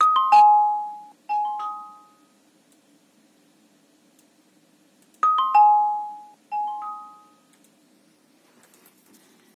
Перегруз при воспроизведении звука Focusrite 8i6
Такое ощущение будто на ЮСБ входе карты или на ЦАП поставили дистершн.
Прикрепил файл: сначала перегруз, потом чистый звук.